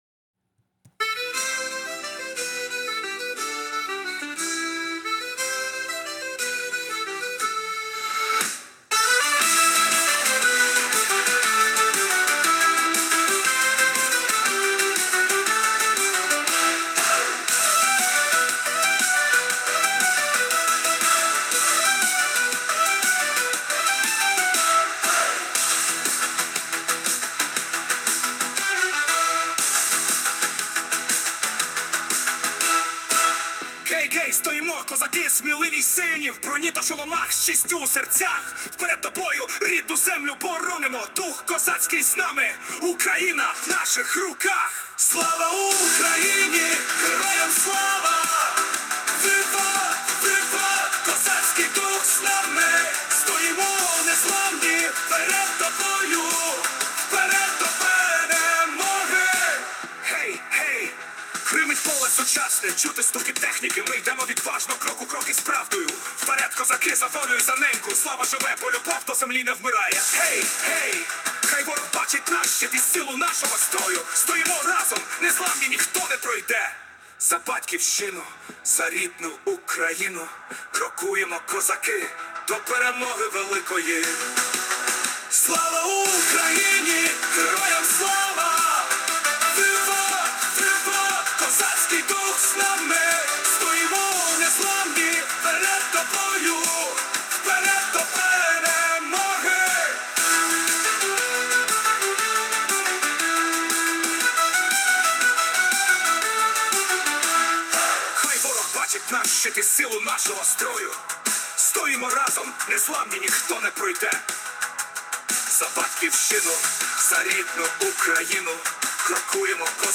Вперед, козаки! Вперед, відважні! ( ( марш)